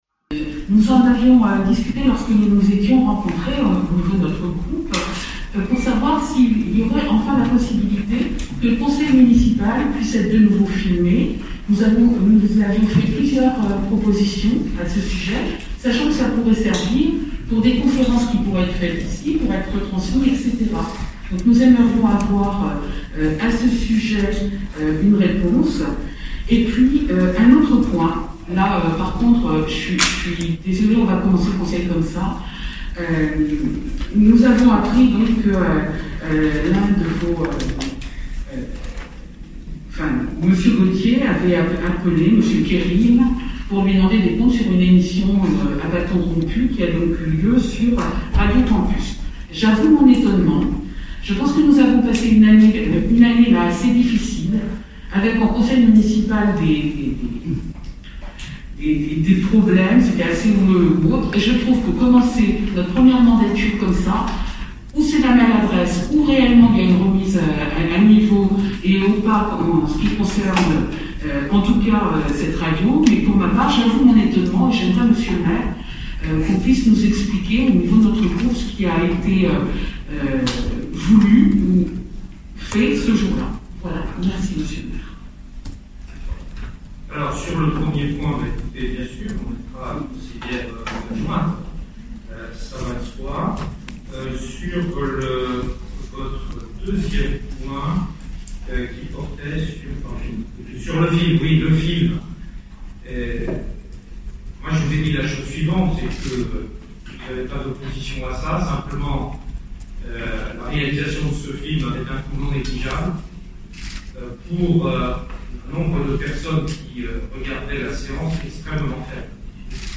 Conseil municipal à Orléans : le retour de Serge Grouard - Mag'Centre
L’intervention de Serge Grouard à propos de Radio Campus